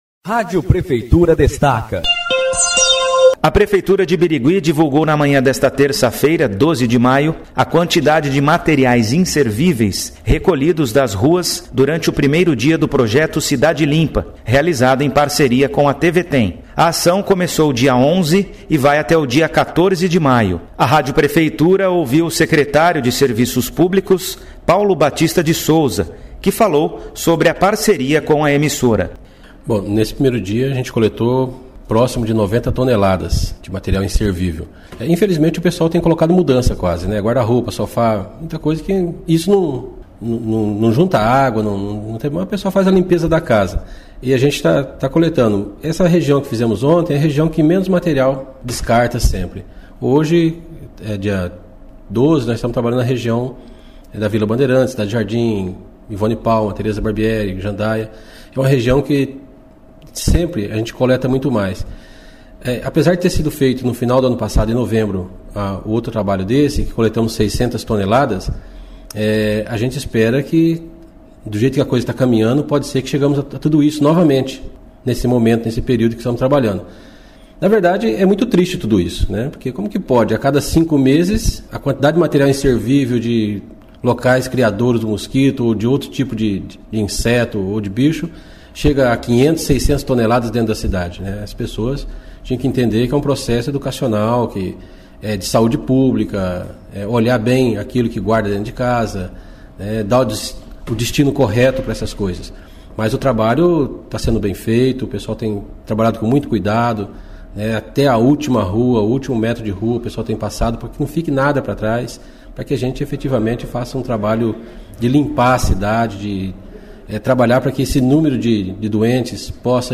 A Rádio Prefeitura falou com secretário de Serviços Públicos, Paulo Batista de Souza, sobre o projeto Cidade Limpa.